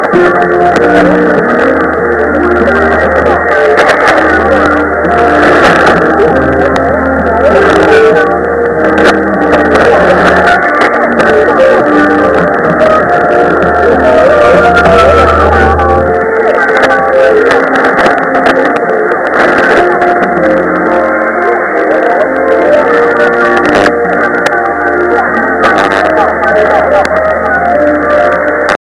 マダガスカル （5010.1）の終了もクリアーに聞けて、大満足。